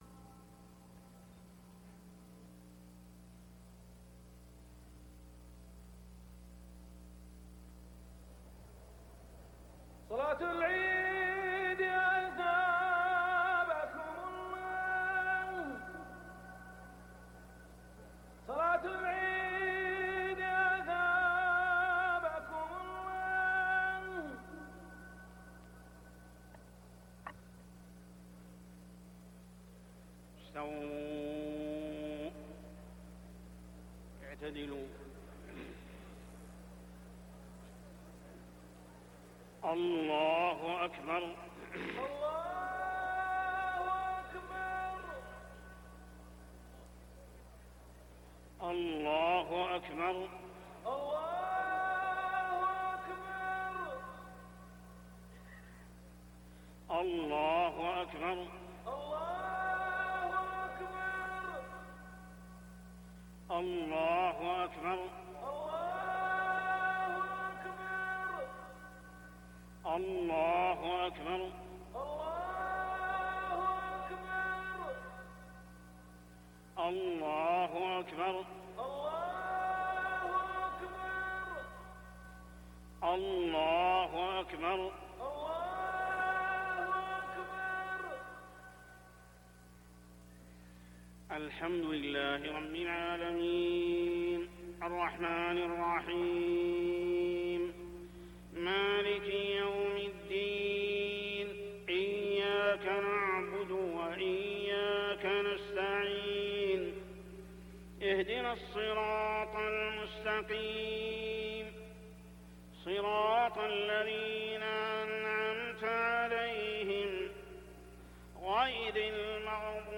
صلاة العيد 1 شوال 1420هـ سورتي الأعلى و الغاشية > 1420 🕋 > الفروض - تلاوات الحرمين